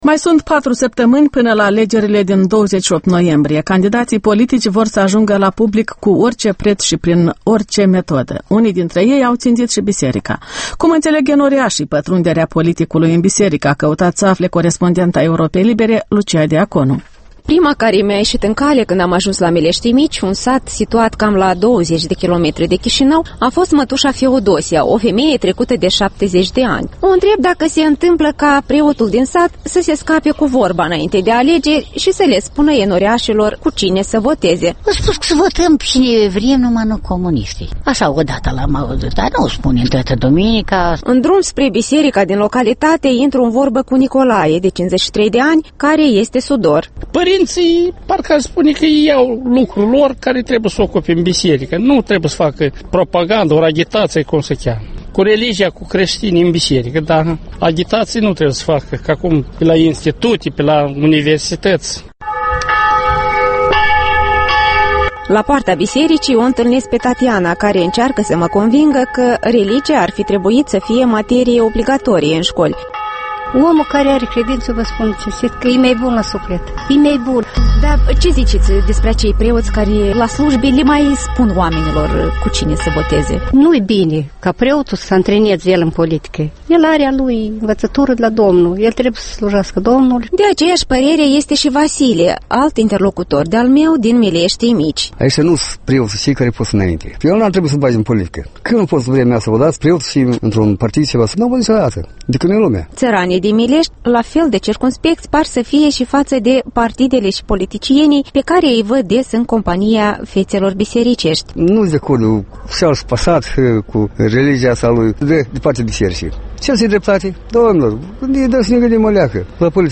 Reportaj Electorala 2010: la Mileștii Mici